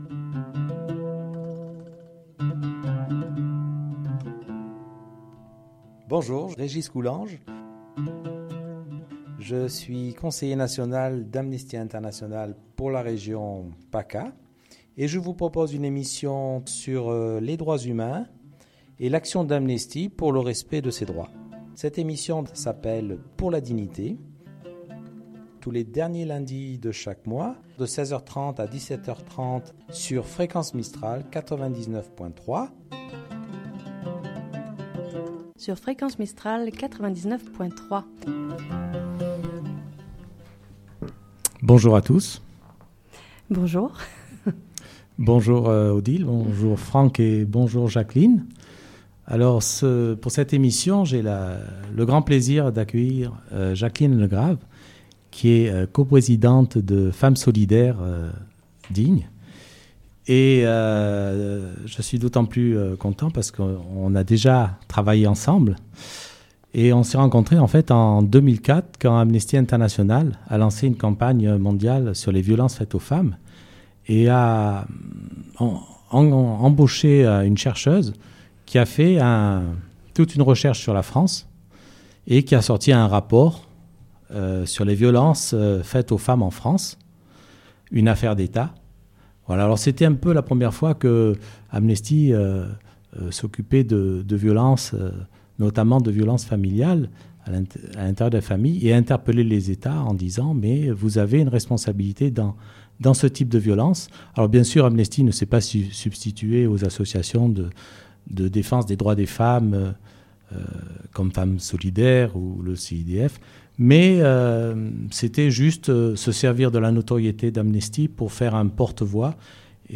Pause musicale avec Beethowen